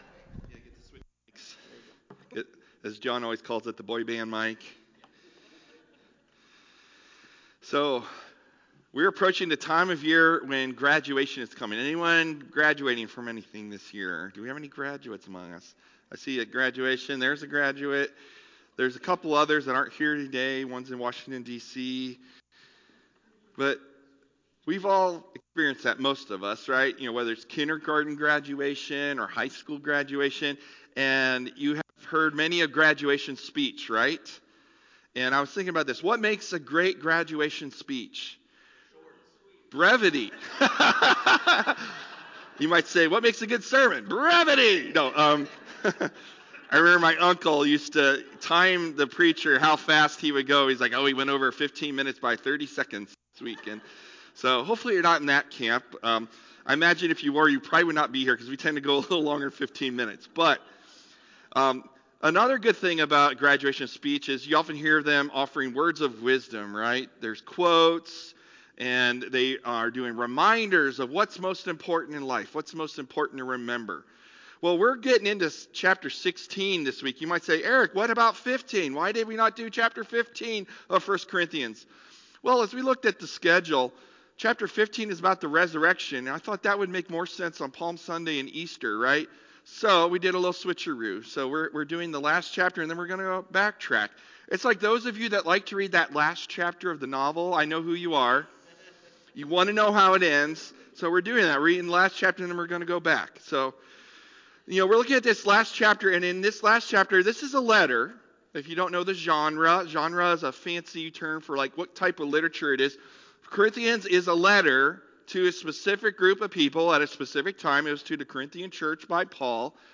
Sermon Passage: 1 Corinthians 16:1-24